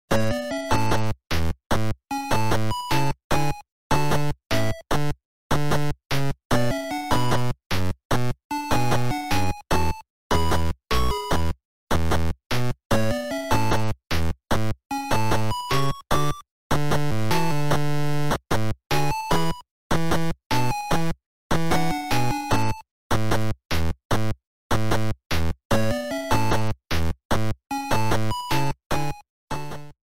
Trimmed to 30 seconds, applied fadeout